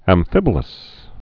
(ăm-fĭbə-ləs)